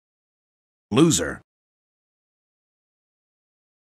Loser Sound
meme